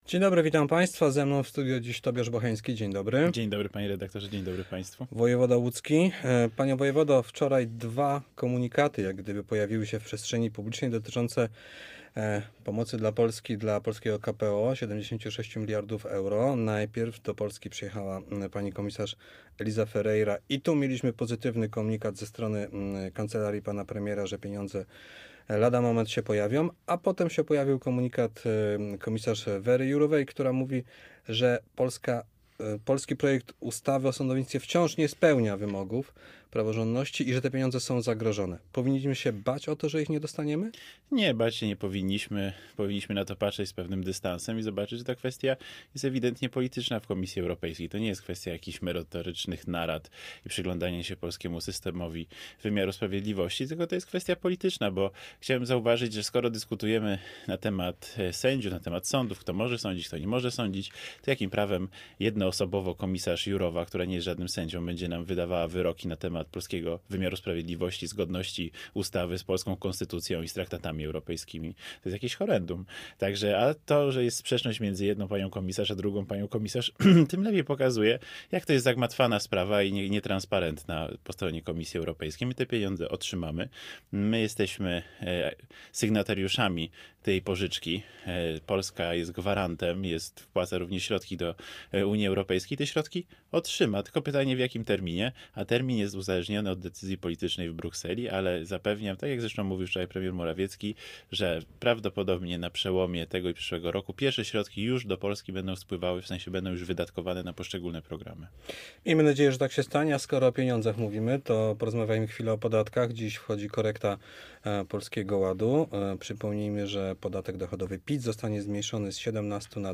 W piątek Gościem Po 8 był Tobiasz Bocheński, łódzki wojewoda.